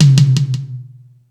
Space Drums(26).wav